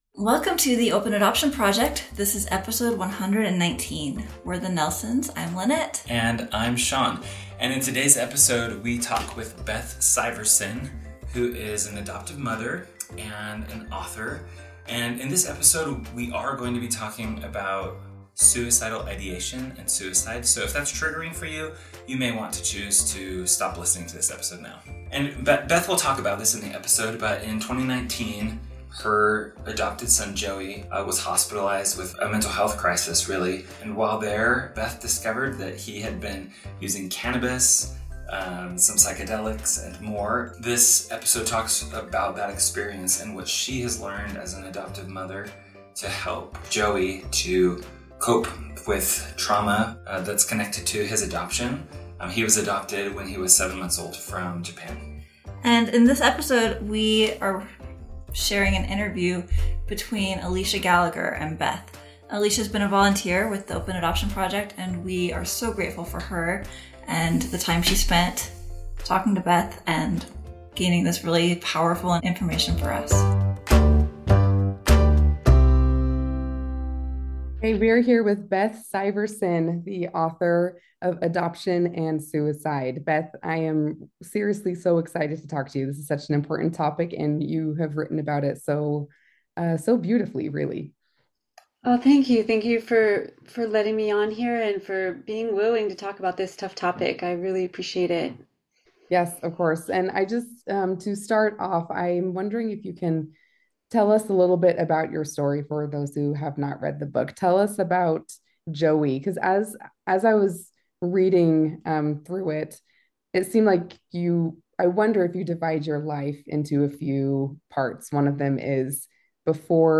An Adoption Triad Interview.